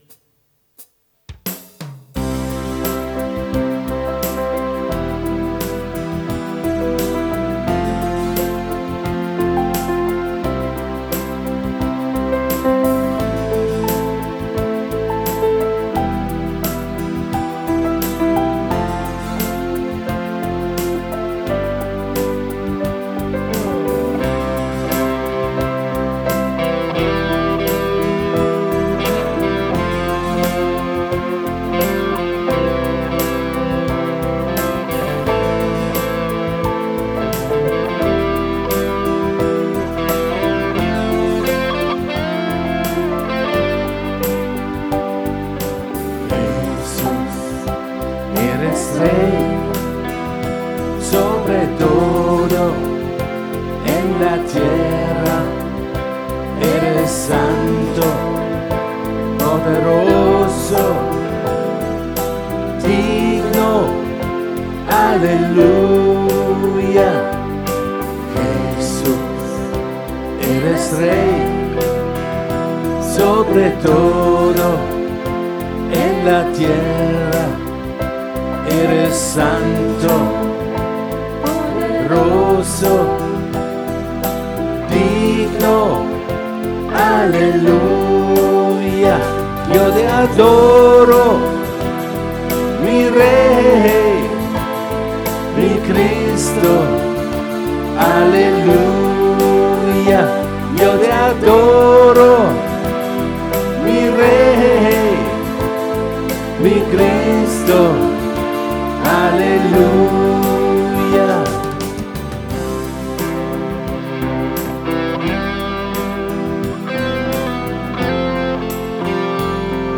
Lobpreis - Jesus Gemeinde Bamberg
Lobpreise der JGB